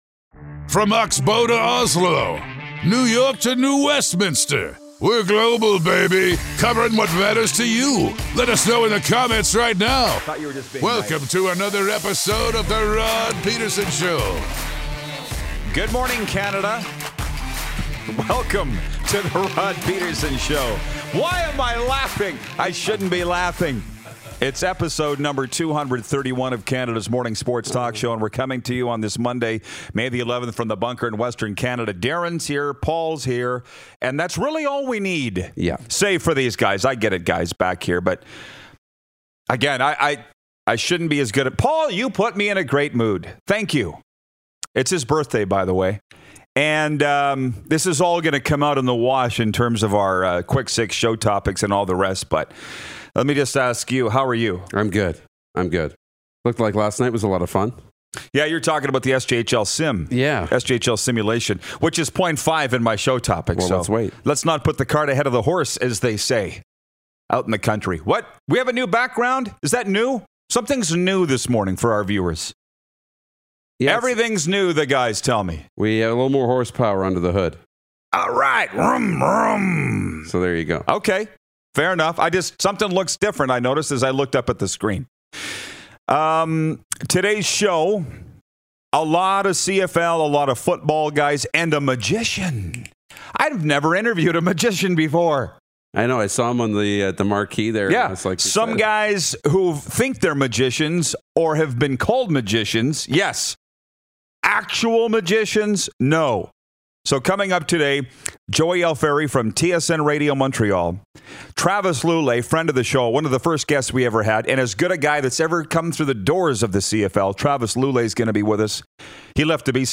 Going LIVE on a Monday with a solid lineup!